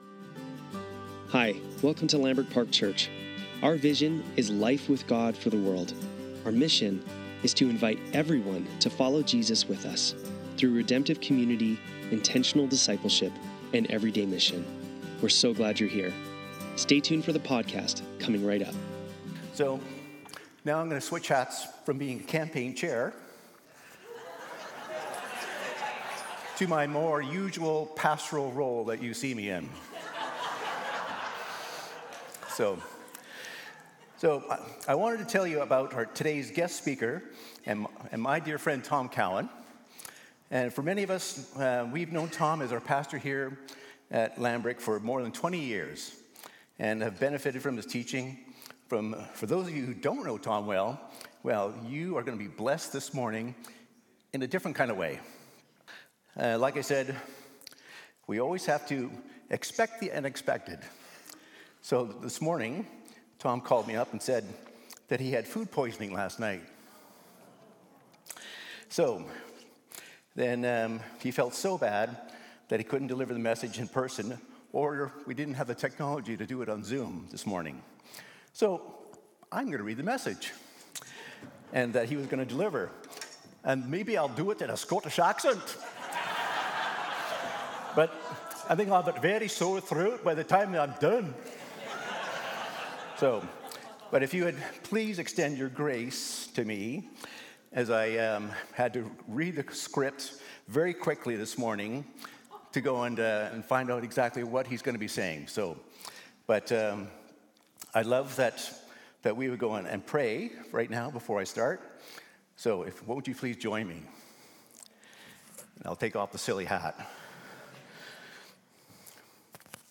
At the Feet of Jesus Current Sermon